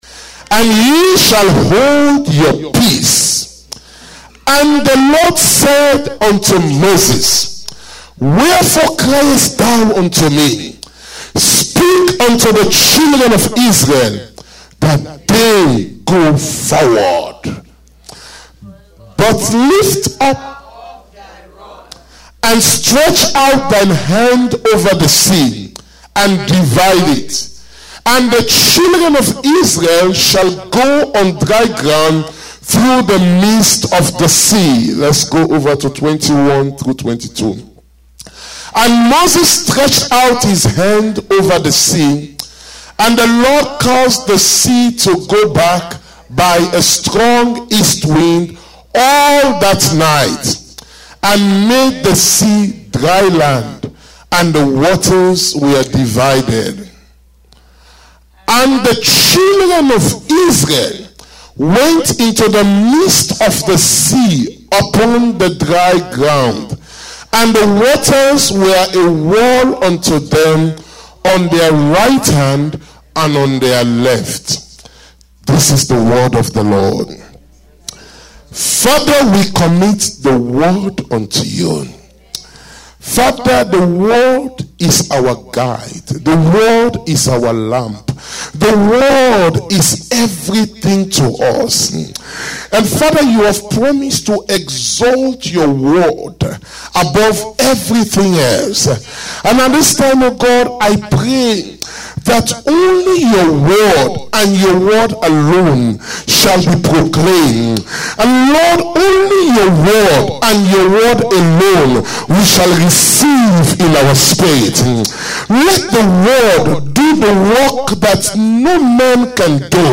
Sermons - Berachah Ministries